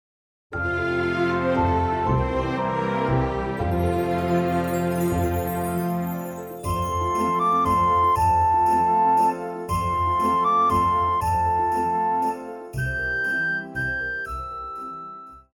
古典
高音直笛
樂團
聖誕歌曲,聖歌,教會音樂,古典音樂
鋼琴曲,演奏曲
獨奏與伴奏
有主奏
有節拍器